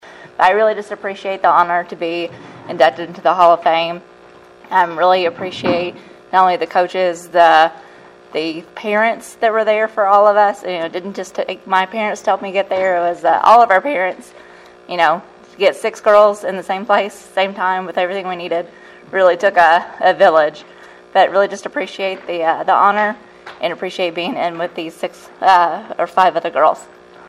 acceptance speech